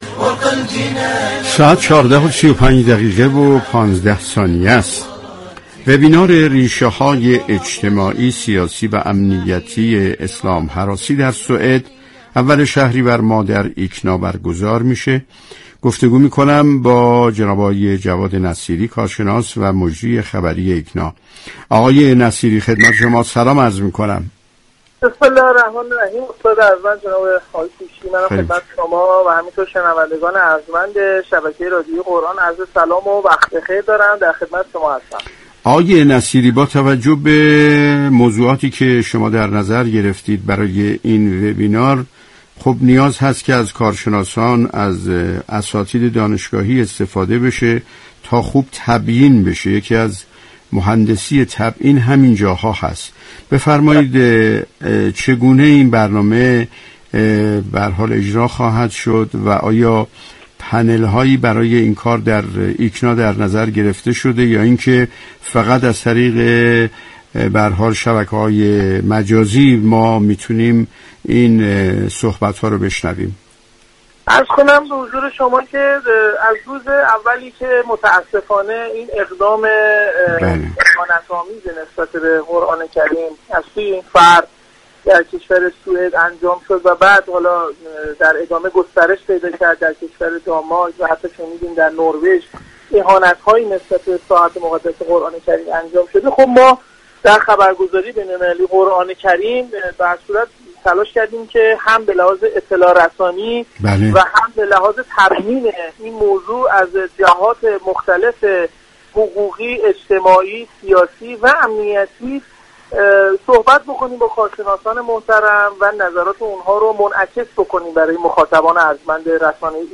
در گفتگو با برنامه والعصر